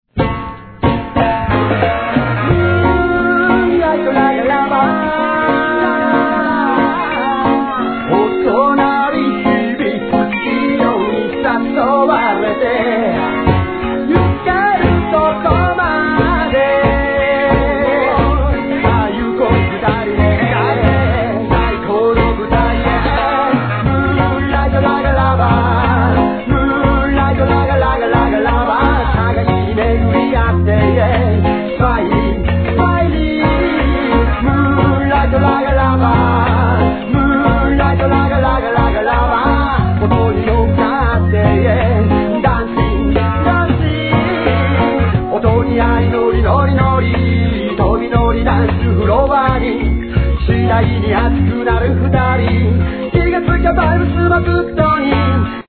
JAPANESE REGGAE